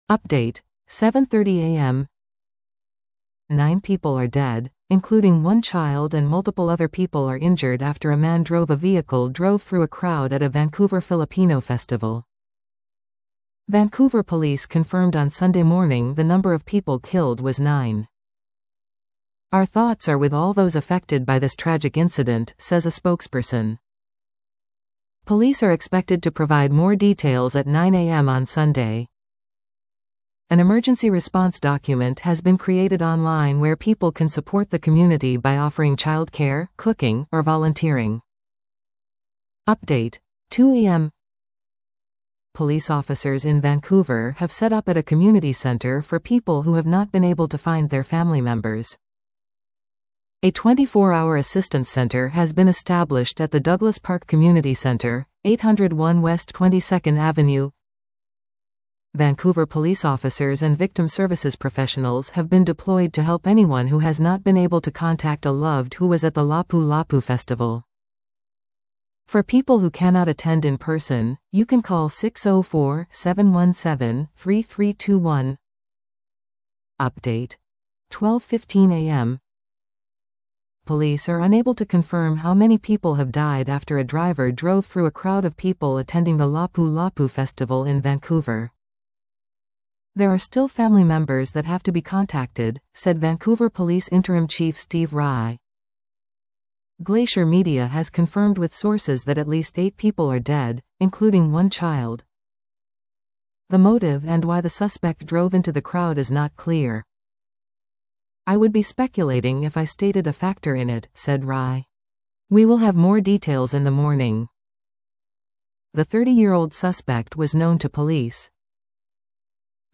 Vancouver Police Department (VPD), BC Premier David Eby speak after deadly car attack at Vancouver Lapu Lapu Day block party; "darkest day in our city."